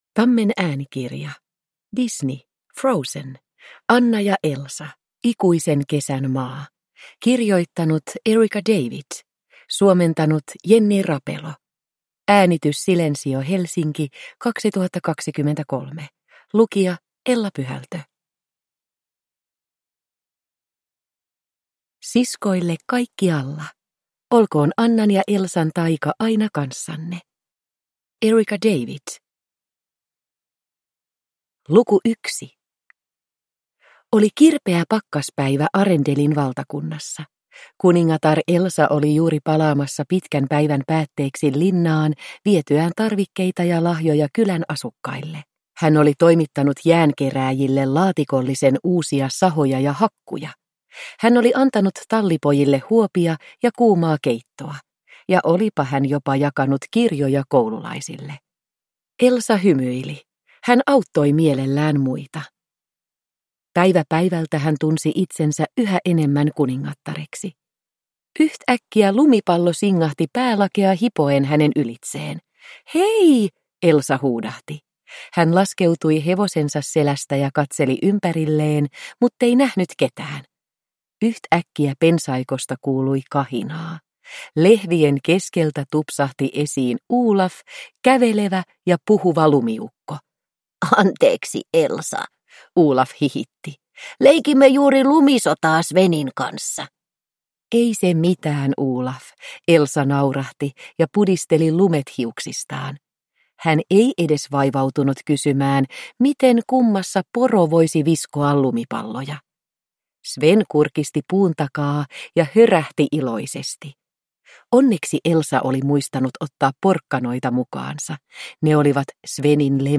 Frozen. Anna & Elsa. Ikuisen kesän maa – Ljudbok – Laddas ner